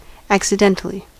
Käännös Konteksti Ääninäyte Adjektiivit 1. accidental US Adverbit 2. accidentally US 3. by chance 4. by accident 5. fortuitously 6. as it happens idiomaattinen 7. by coincidence 8. incidentally 9. perchance